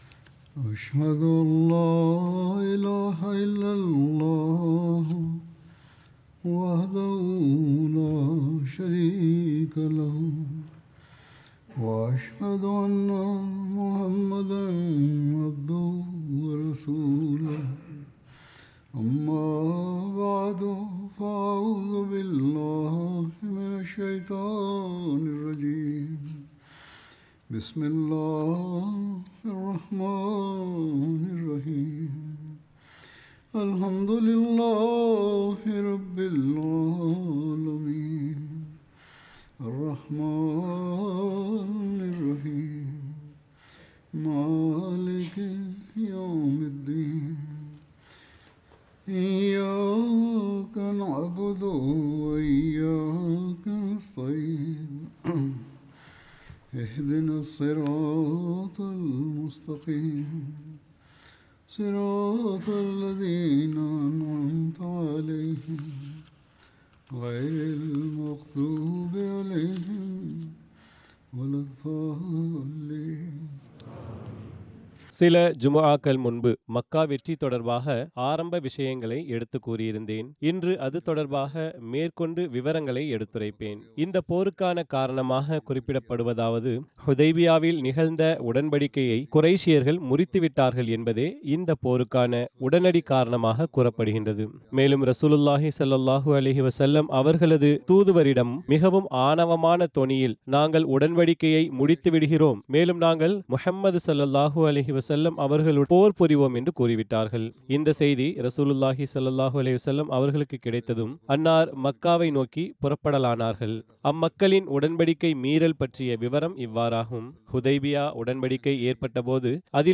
Tamil Translation of Friday Sermon delivered by Khalifatul Masih